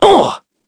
Evan-Vox_Damage_01.wav